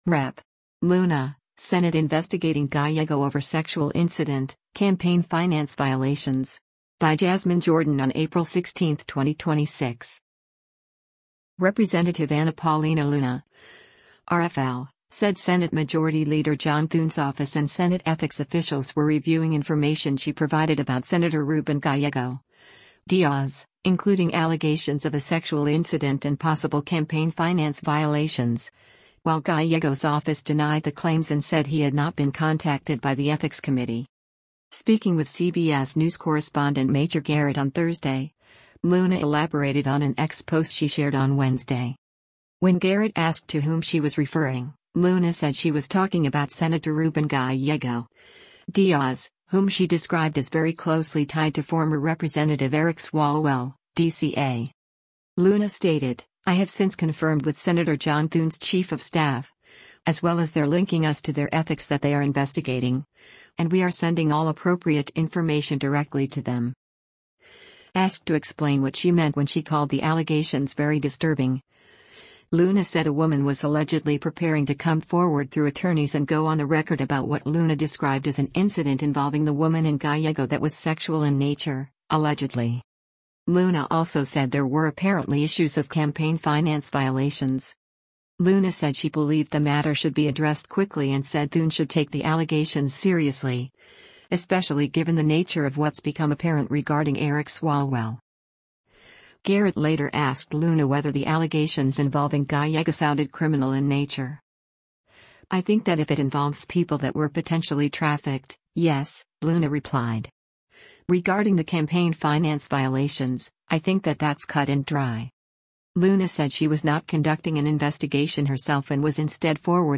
Speaking with CBS News correspondent Major Garrett on Thursday, Luna elaborated on an X post she shared on Wednesday: